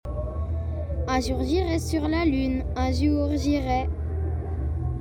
Nyon // été 2019 La Ruche du Paléo Festival
Les sonoportraits
Chant en français, le plus court de la sonothèque nomade